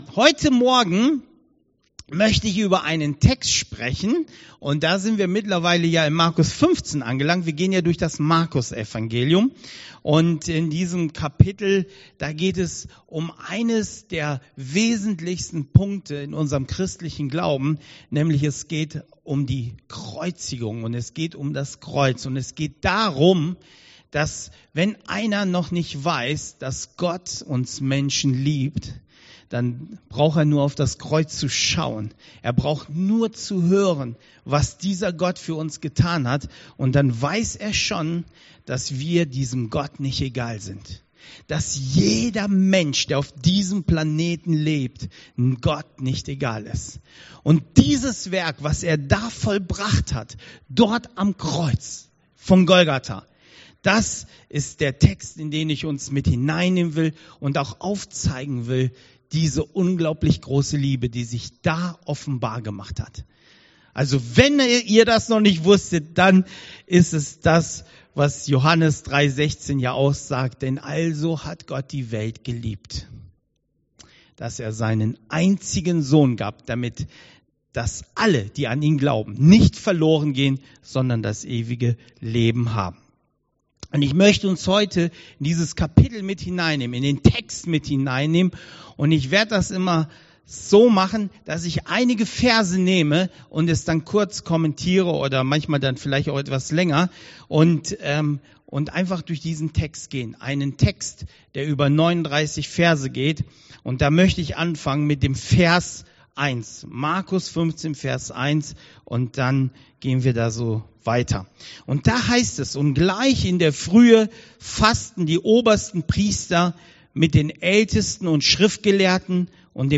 Predigt 25.07.2021